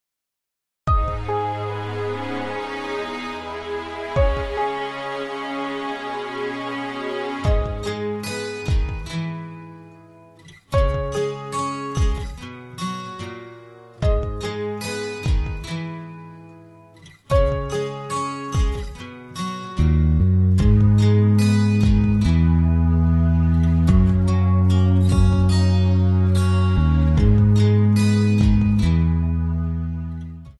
Backing track files: 2000s (3150)
Buy With Backing Vocals.
Buy Without Backing Vocals